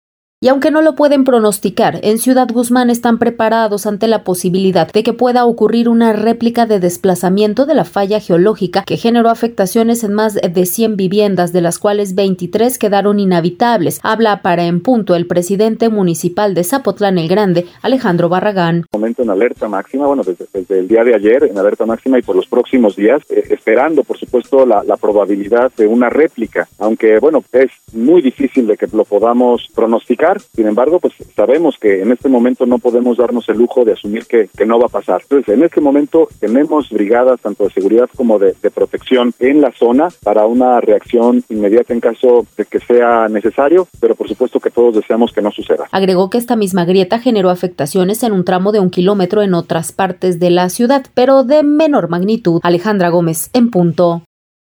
Y aunque no lo pueden pronosticar, en Ciudad Guzmán están preparados ante la posibilidad de que pueda ocurrir una réplica de desplazamiento de la falla geológica que generó afectaciones en más de 100 viviendas, de las cuales 23 quedaron inhabitables. Habla para empunto el presidente municipal de Zapotlán el Grande, Alejandro Barragán.